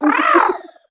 cetoddle_ambient.ogg